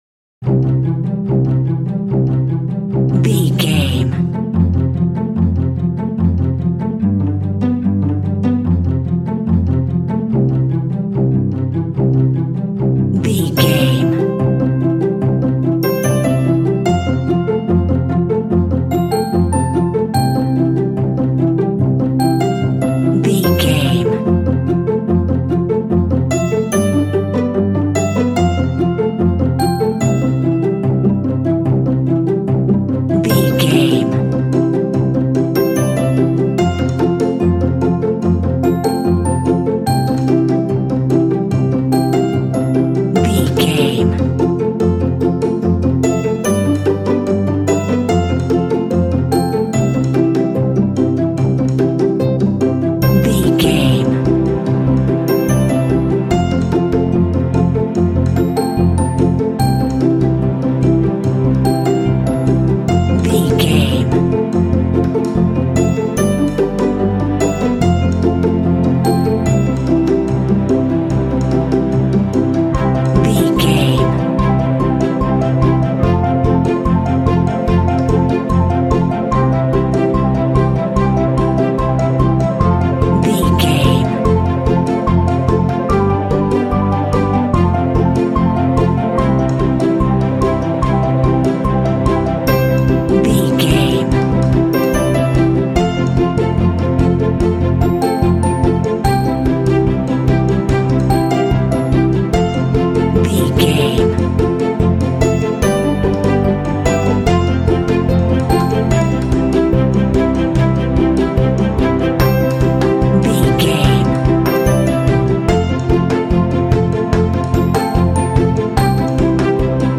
Ionian/Major
Fast
energetic
strings
piano
percussion
contemporary underscore